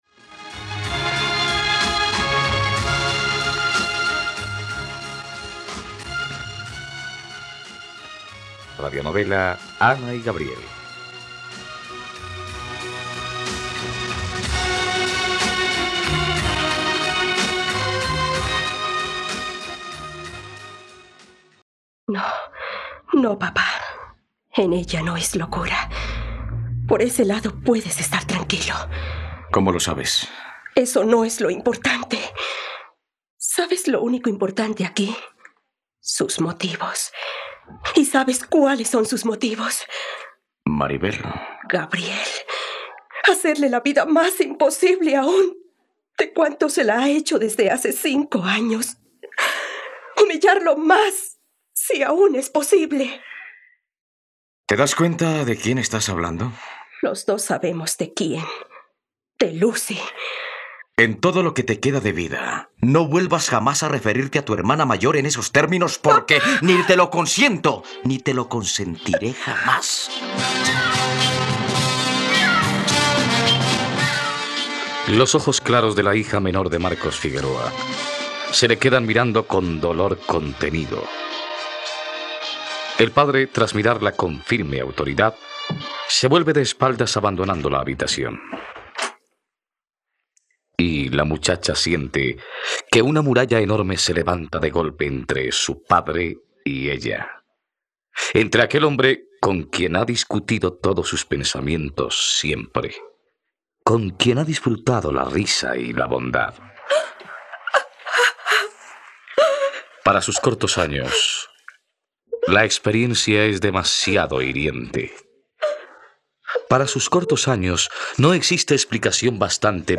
..Radionovela. Escucha ahora el capítulo 37 de la historia de amor de Ana y Gabriel en la plataforma de streaming de los colombianos: RTVCPlay.